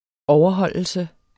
Udtale [ ˈɒwʌˌhʌlˀəlsə ]